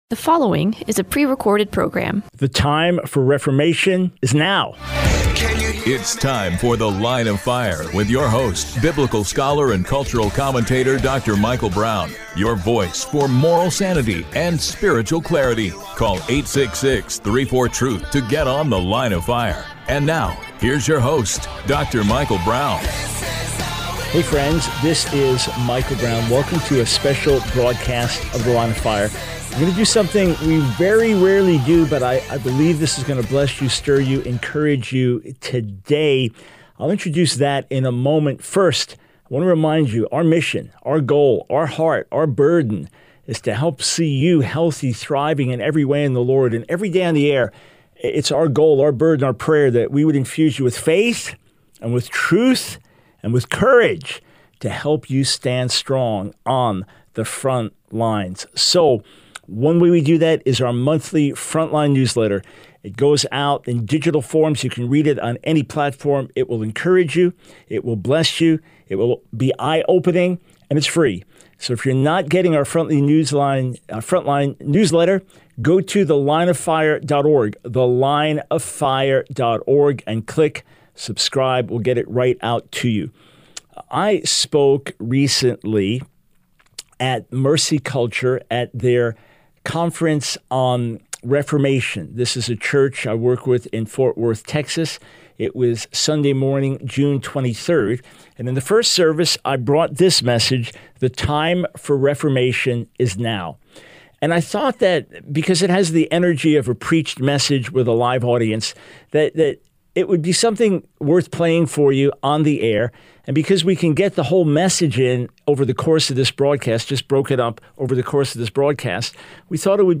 The Line of Fire Radio Broadcast for 07/02/24.